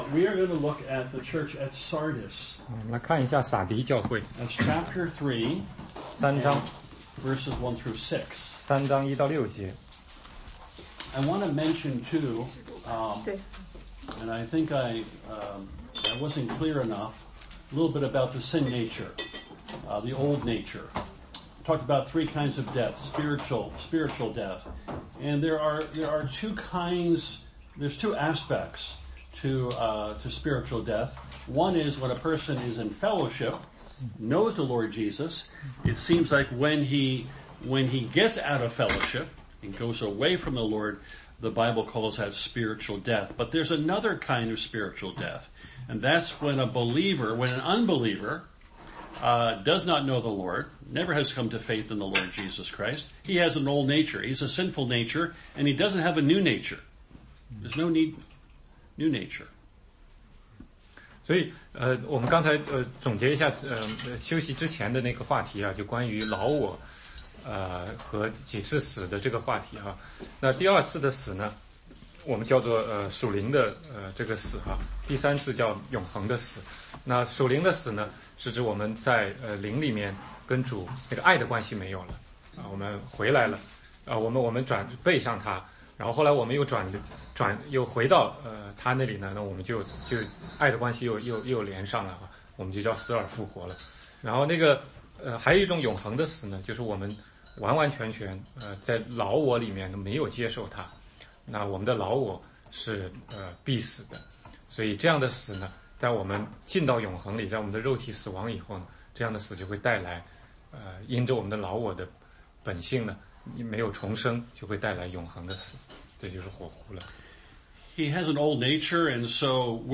16街讲道录音 - 启示录七封书信之撒狄教会